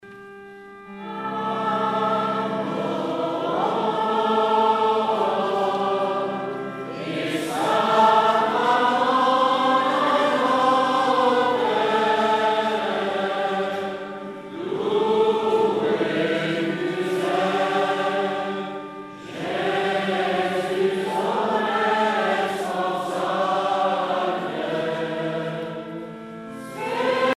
prière, cantique
Pièce musicale éditée